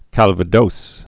(kălvə-dōs, kălvə-dōs)